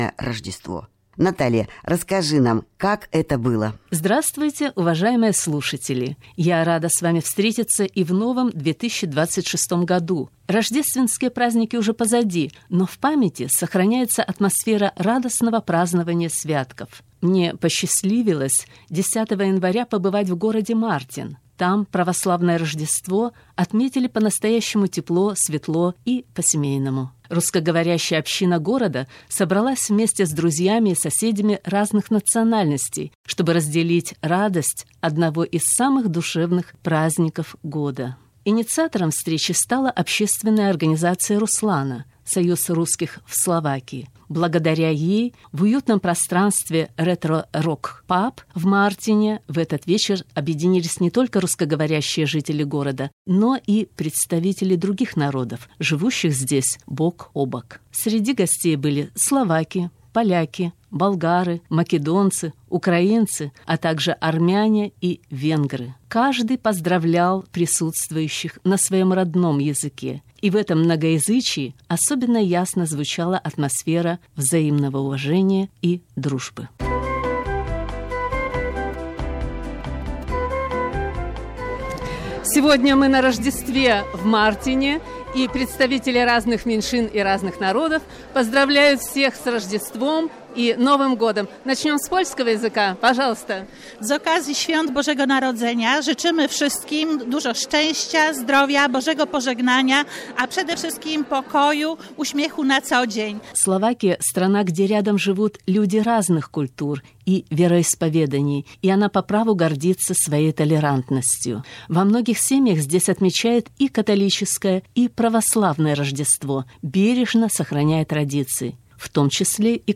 Репортаж о мероприятии на радио RSI RTVS: